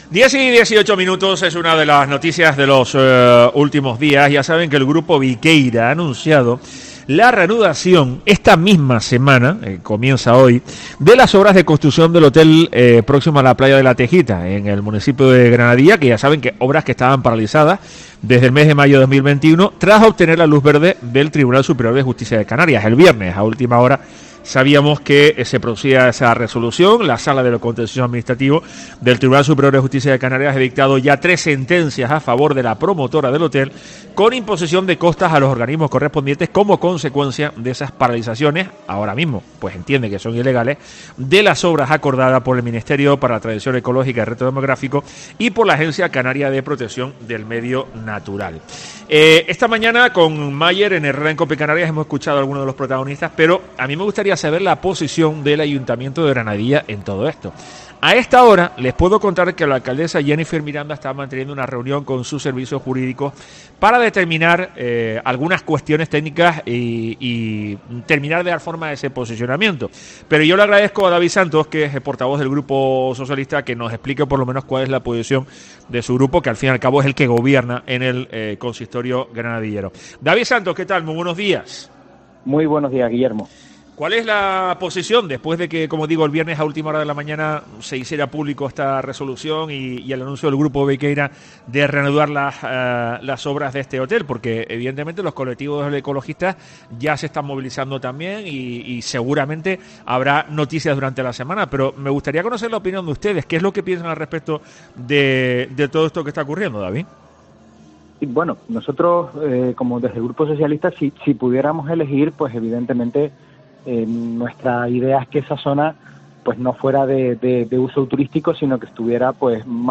Así lo ha puesto de manifiesto este lunes en Herrera en COPE Tenerife el portavoz del Grupo Municipal Socialista, que ostenta la Alcaldía y que gobierna el municipio sureño en coalición con el Partido Popular.